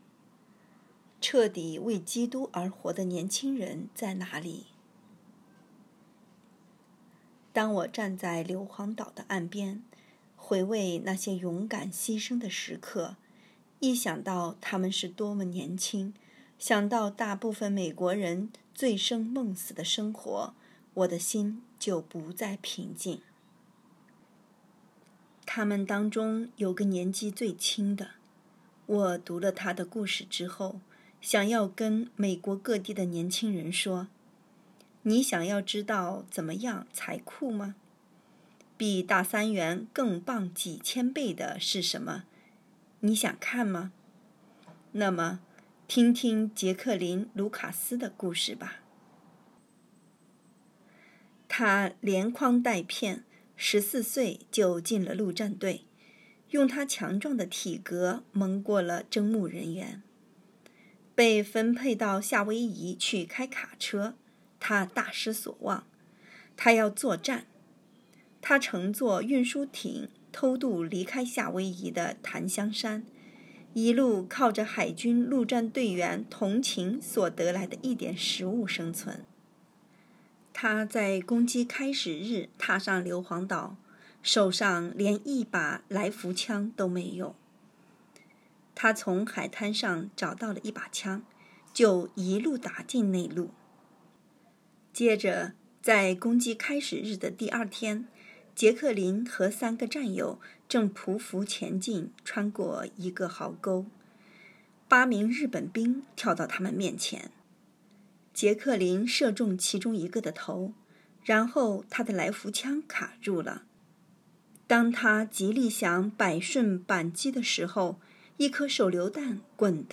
2024年6月18日 “伴你读书”，正在为您朗读：《活出热情》 欢迎点击下方音频聆听朗读内容 音频 https